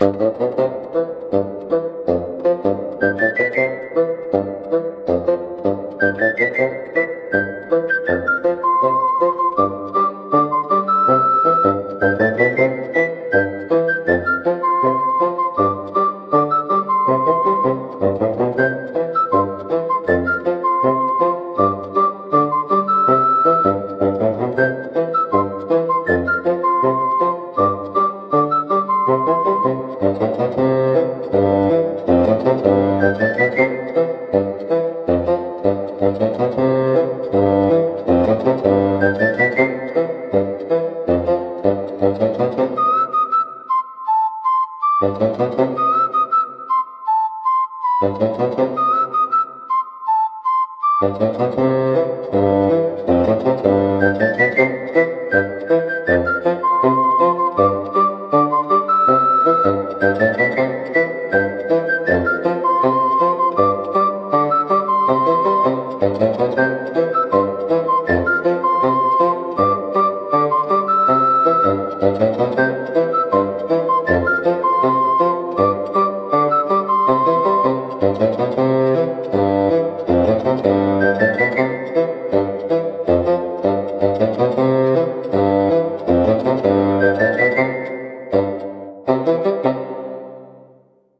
おとぼけは、バスーンとシロフォンを主体としたコミカルでドタバタした音楽ジャンルです。